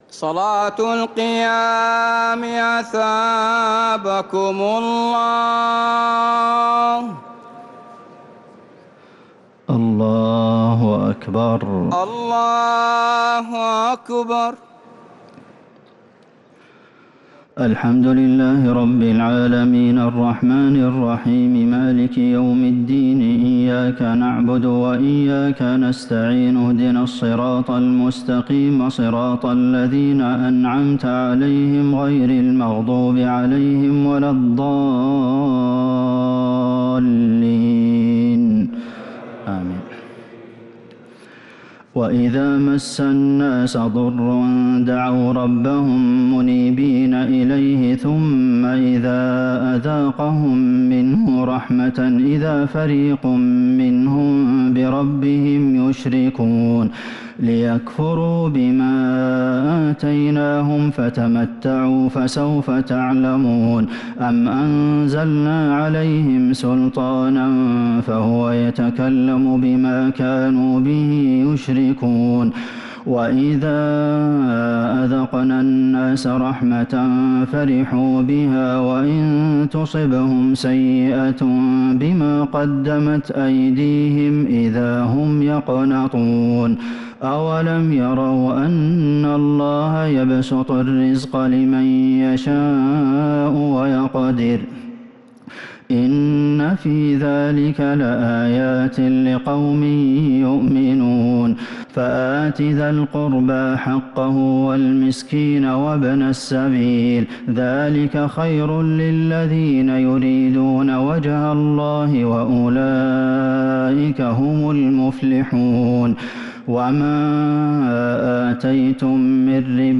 تراويح ليلة 24 رمضان 1447هـ من سورة الروم (33_60) إلى سورة السجدة كاملة | taraweeh 24th night Ramadan 1447H surah Ar-Rum to as-Sajdah > تراويح الحرم النبوي عام 1447 🕌 > التراويح - تلاوات الحرمين